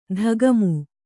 ♪ dhagamu